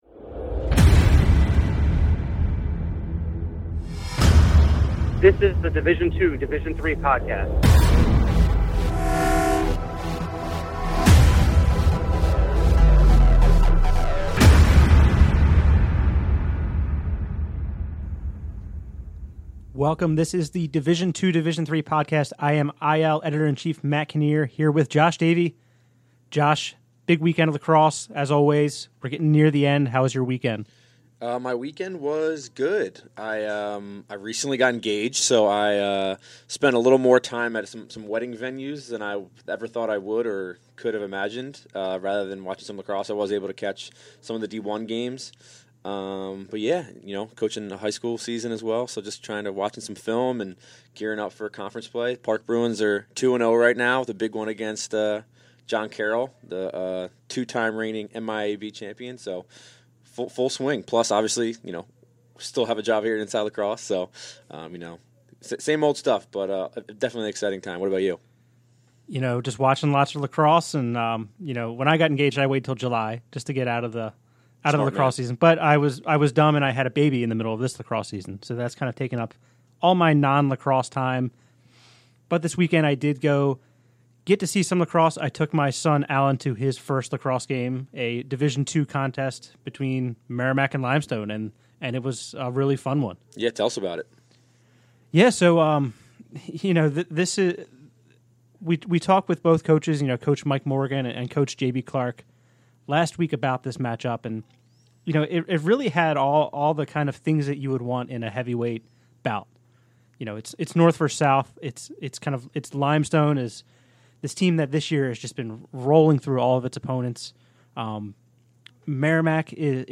Also, a discussion on Merrimack's win over Limestone, and a look at some of the DIII conference tourney scenarios.